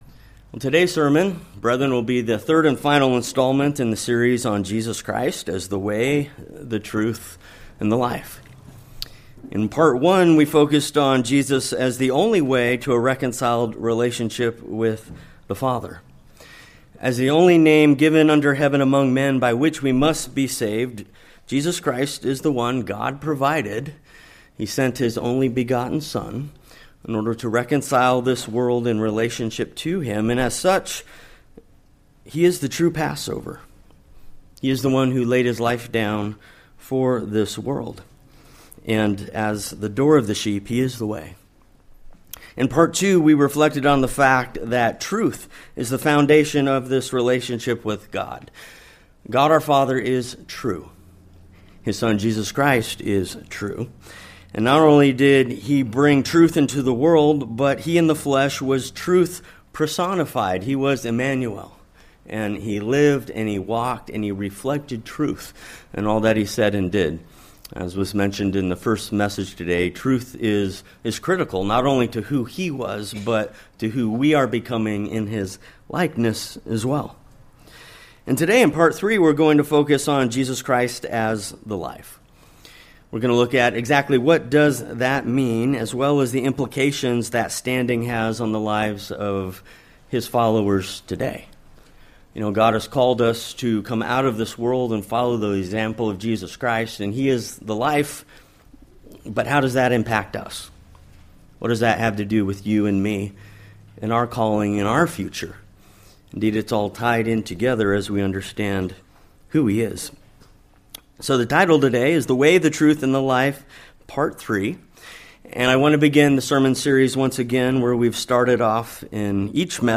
This is part 3 in the sermon series with a focus on Jesus Christ as “the life.” As the One through whom God created all things, life exists because of Him. Likewise, partaking of Him as the bread of life is the only way that we can live eternally in the family of God.